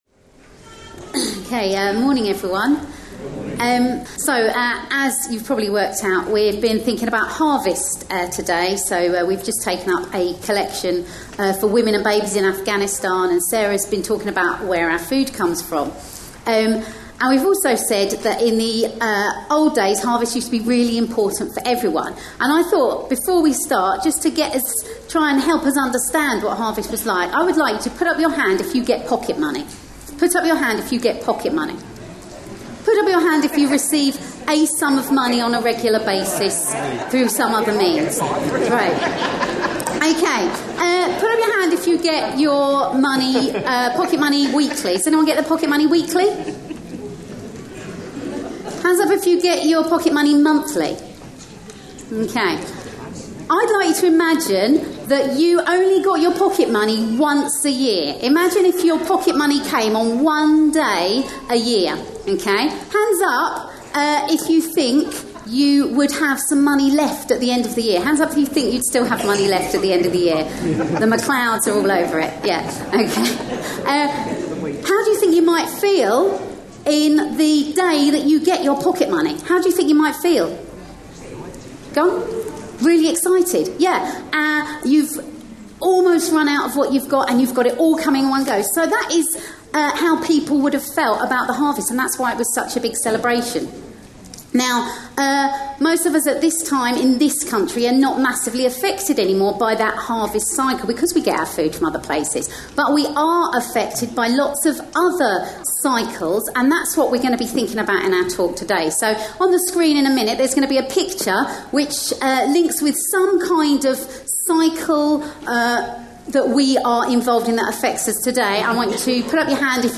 A sermon preached on 23rd September, 2018.
Ecclesiastes 3:1-11 Listen online Details This talk was part of an all-age service for harvest; reading is from Ecclesiastes 3:1-11 ("A Time for Everything", starting about 6 mins in) with references to Revelation 21:3-4, Ecclesiastes 12:1, and Philippians 4:11-13.